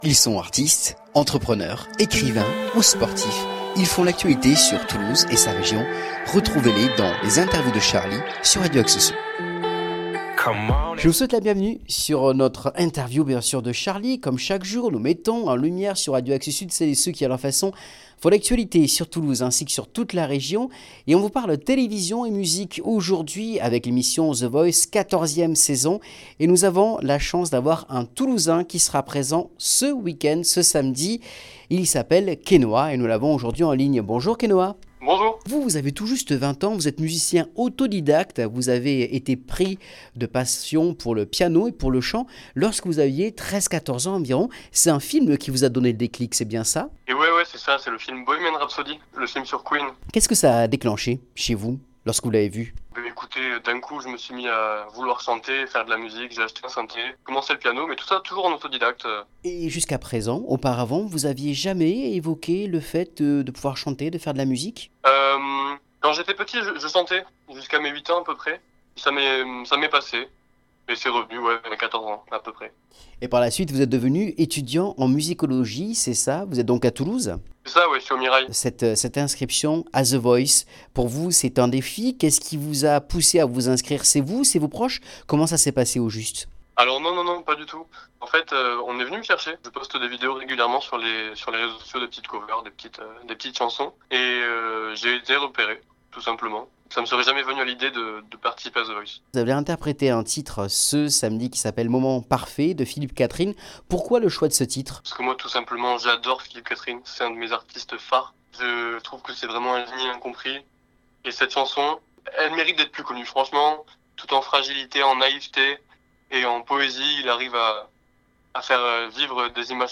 Interview Exclusive Spécial The Voice
Interview Exceptionnelle sur Radio Axe Sud.